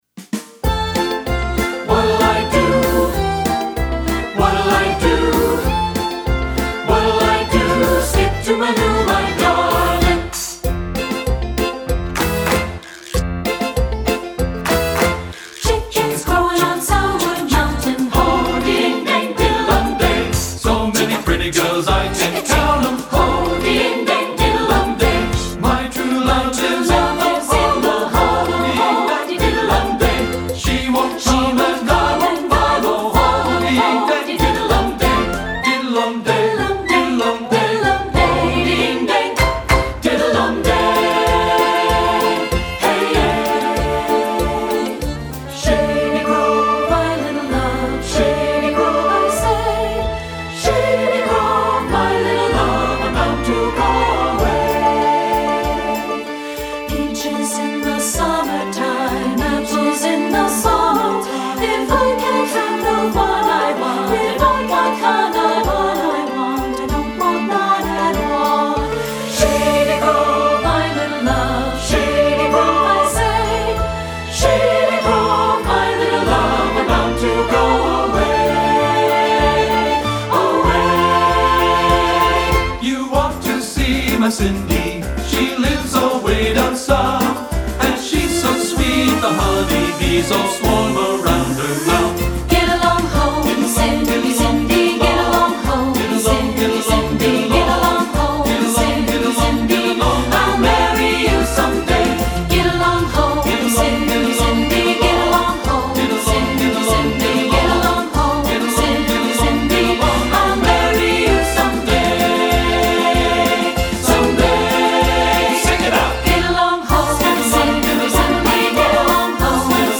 Choral Folk
3 Part Mix